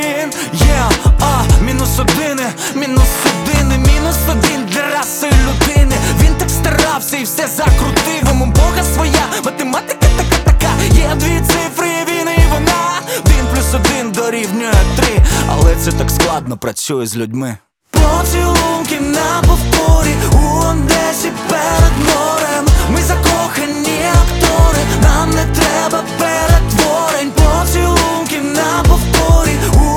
Жанр: Поп / Украинский рок / Украинские